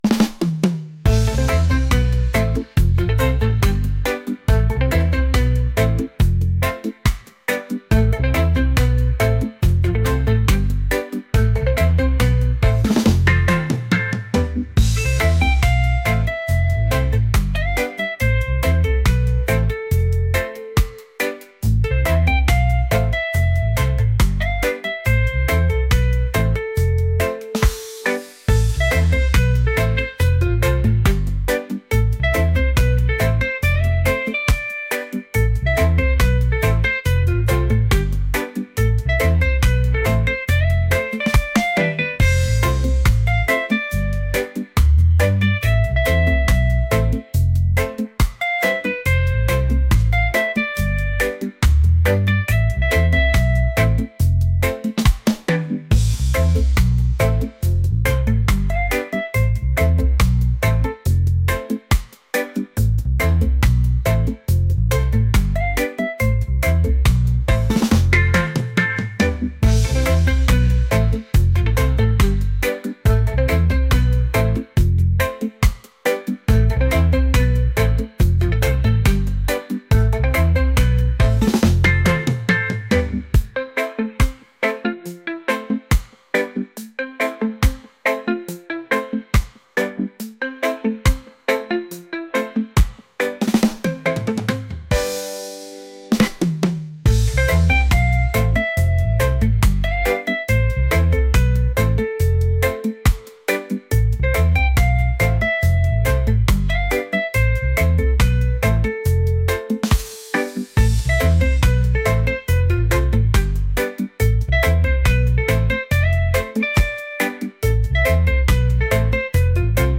relaxed | reggae | groovy